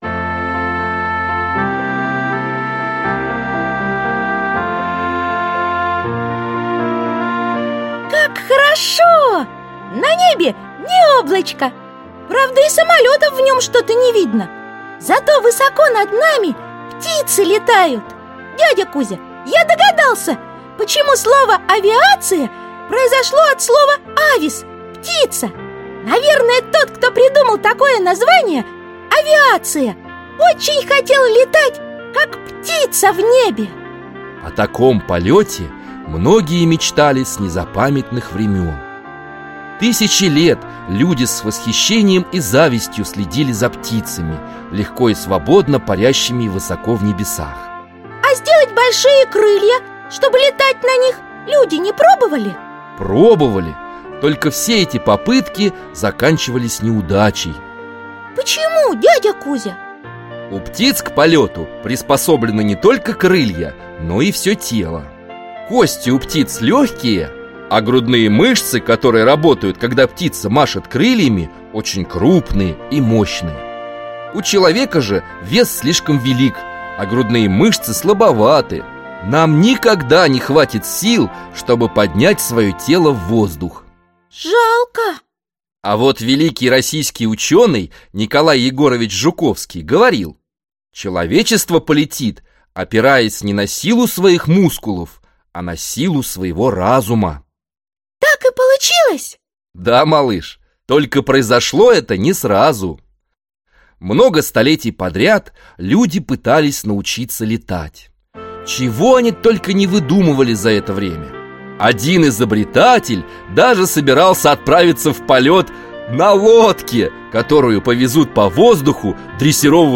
Aудиокнига Самолеты и авиация Автор Детское издательство Елена.